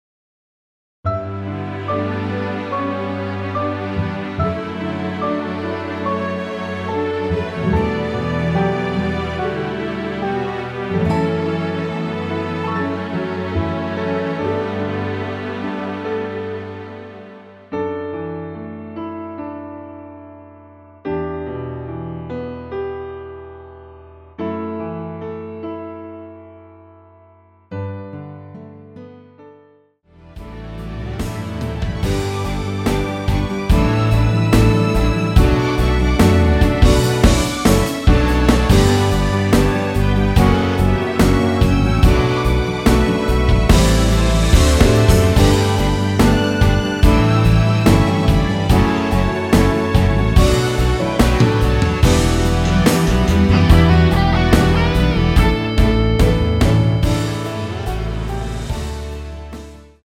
원키 MR
앞부분30초, 뒷부분30초씩 편집해서 올려 드리고 있습니다.